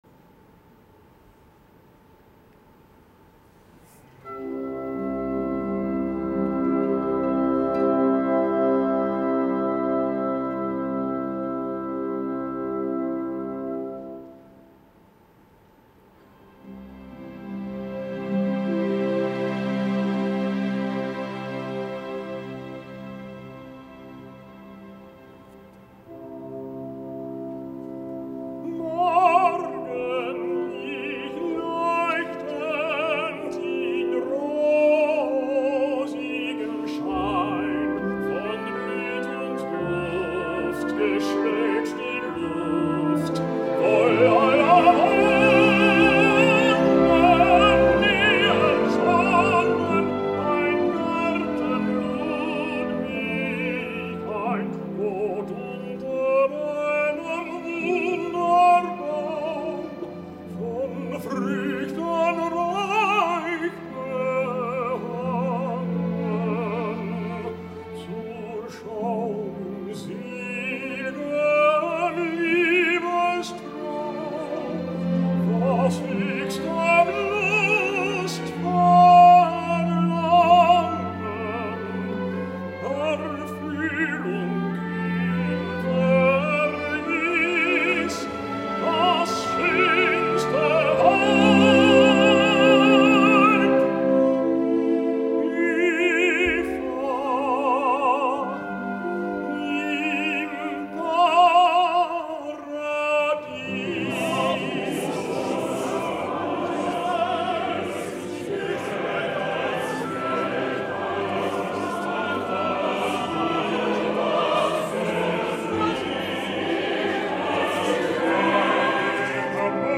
Buscava un nou cantant que ens donés una satisfacció, ja que he trobat una producció de Die Meistersinger von Nürnberg amb cantants desconeguts i molt actual, i he pensat, “mira que si trobes el Walther von Stolzing del futur!” i malauradament no ha estat així, ans al contrari.
Escoltem aquest “Morgenlich leuchtend in rosigem schein” del tercer acte de Die Meistersinger von Nürnberg, literalment massacrat